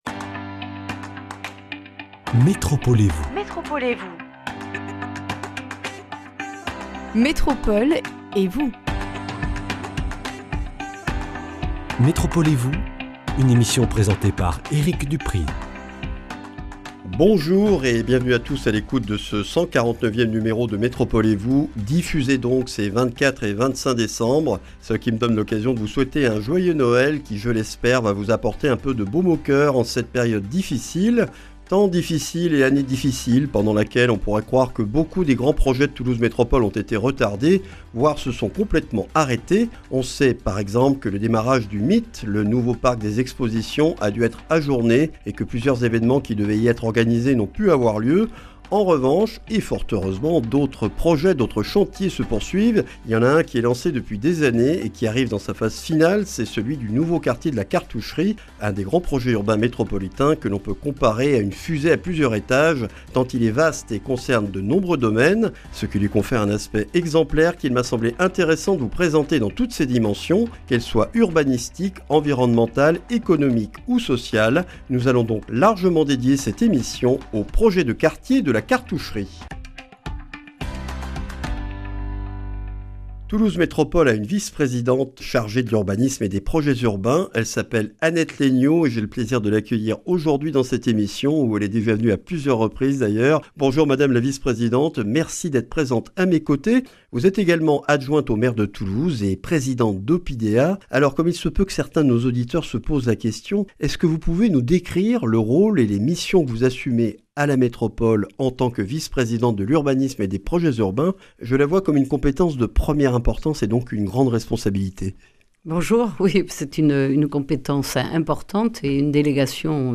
Le nouveau quartier de La Cartoucherie, à l’ouest de Toulouse, est un des grands projets urbains métropolitains des dernières années. Annette Laigneau, adjointe au maire de Toulouse, vice-présidente de Toulouse Métropole chargée de l’Urbanisme et des Projets urbains, nous en présente toutes les dimensions, urbanistiques, environnementales, économiques et sociales.